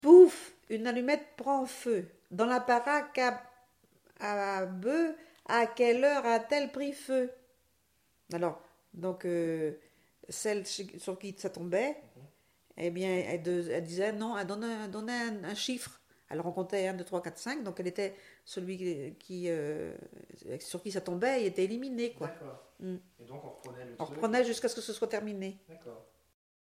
Formulette pour pouffer
Témoignage et chansons
Pièce musicale inédite